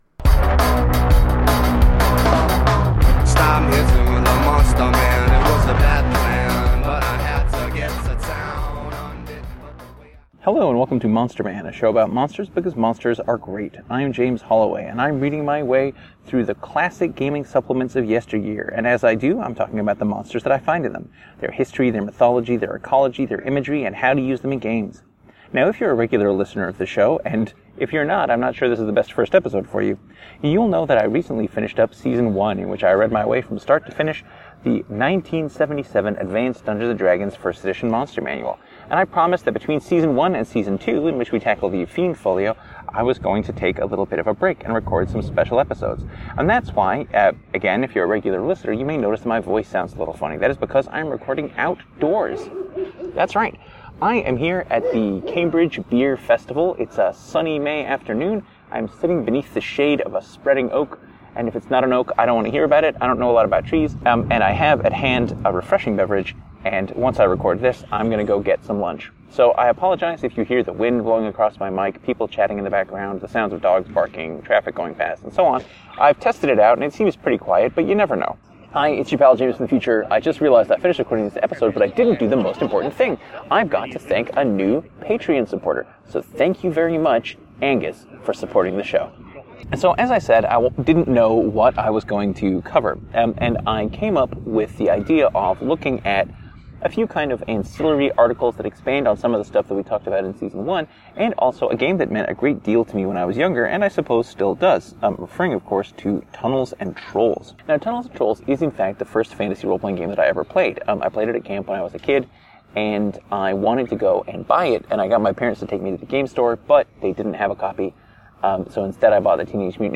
Enjoy this reflection on monsters in Tunnels and Trolls, my first fantasy RPG, recorded in the open air at Cambridge Beer Festival.